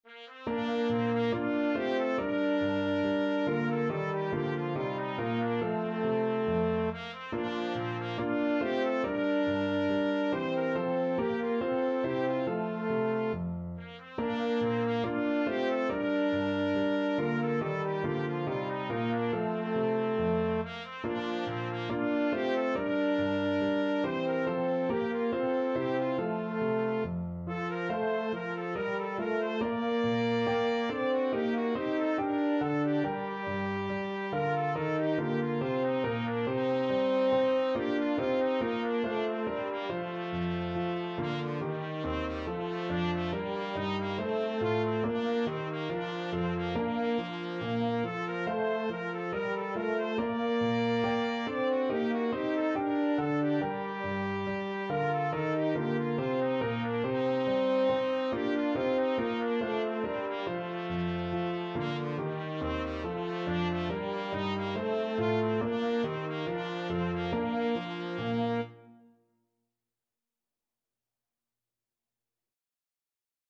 Free Sheet music for Trumpet-French Horn Duet
Bb major (Sounding Pitch) (View more Bb major Music for Trumpet-French Horn Duet )
4/4 (View more 4/4 Music)
Classical (View more Classical Trumpet-French Horn Duet Music)